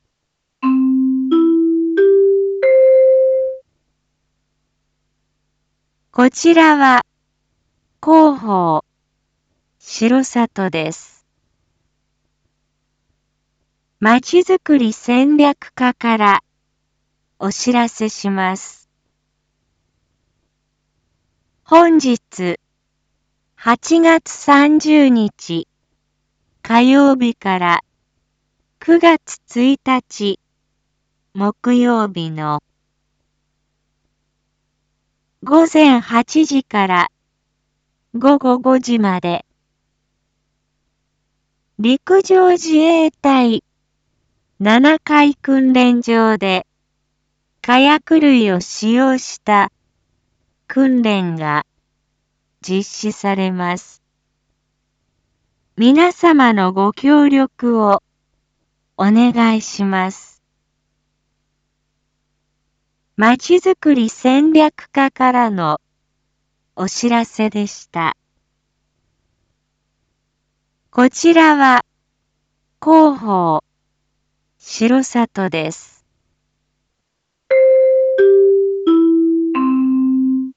Back Home 一般放送情報 音声放送 再生 一般放送情報 登録日時：2022-08-30 07:01:21 タイトル：R4.8.30 7時放送分 インフォメーション：こちらは広報しろさとです。